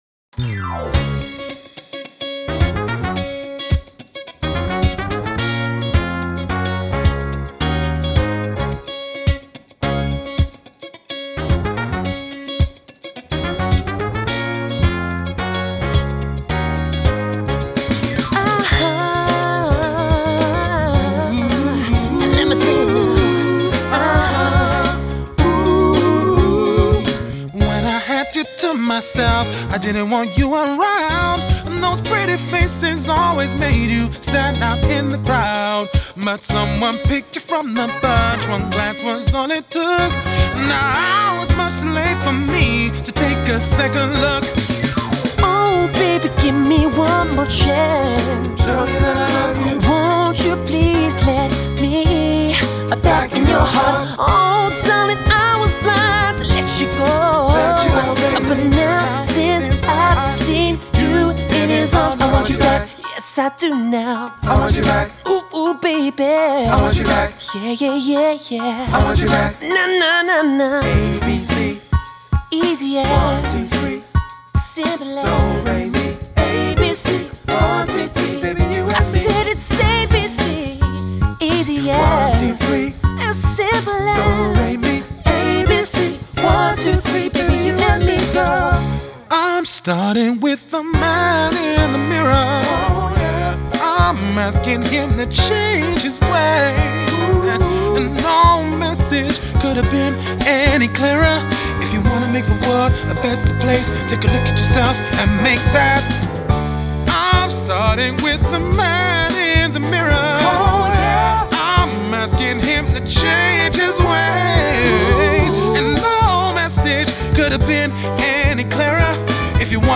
Amazing 9-12 piece Showband